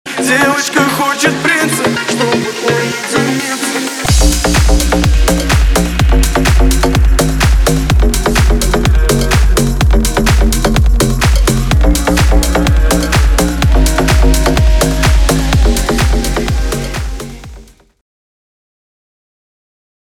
• Качество: 320, Stereo
remix
Club House
басы
качающие
цикличные